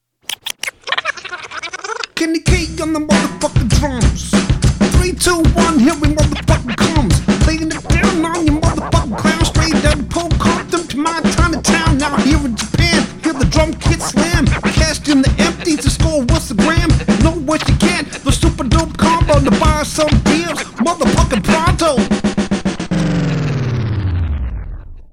Don’t expect polish.
It’s loud.